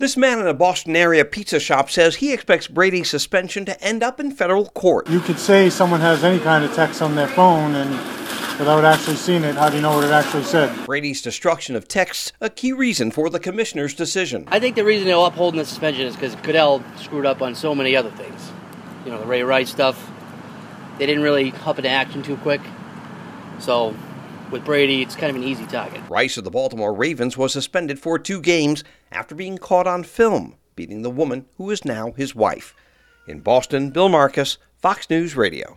Broadway in Somerville, MA where the sound for this story was collected.